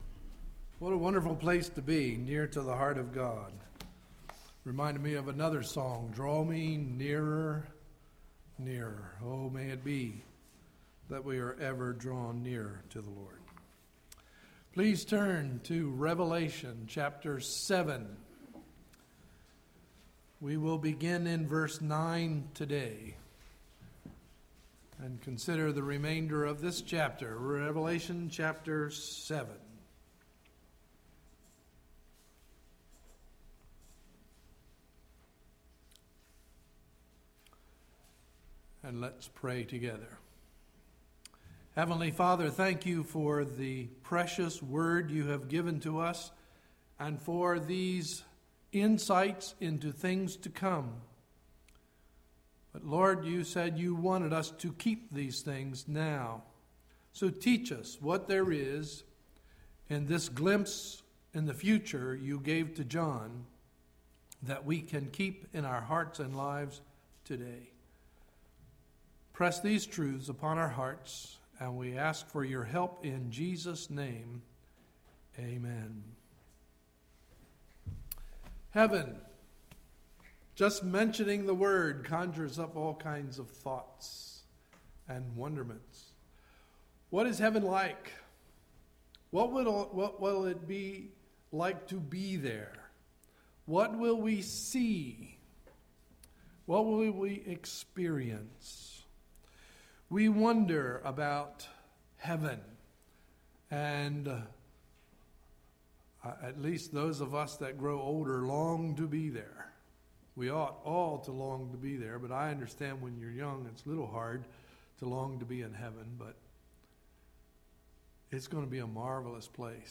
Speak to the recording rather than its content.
Sunday, July 17, 2011 – Morning Message